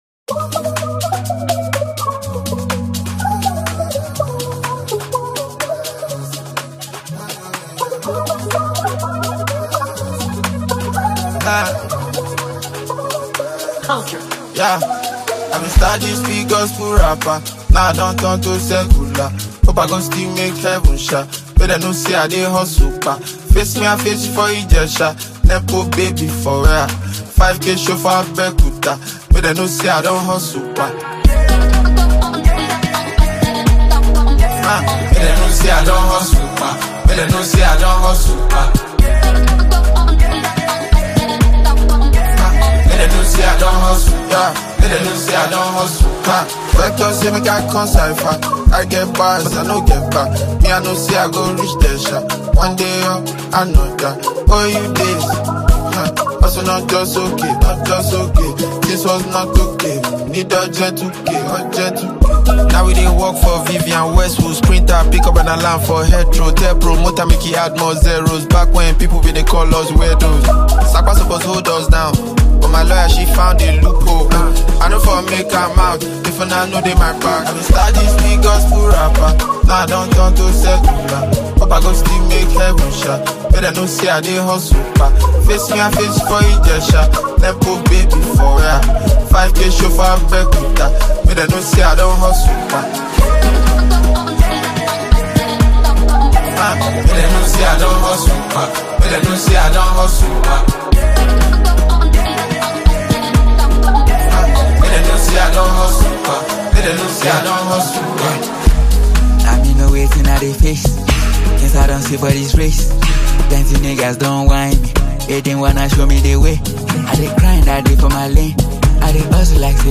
energetic record
a vibrant Afro-fusion sound